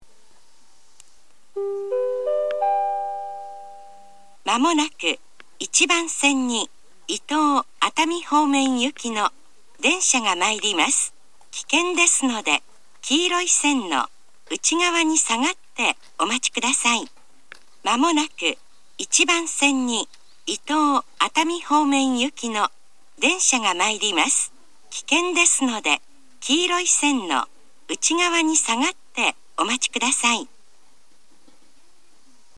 自動放送タイプ
伊豆急仙石型
（女性）
2番線を下り特急が通過する際の交換列車で聞けます。
上り接近放送
Panasonic縦型　1・2番線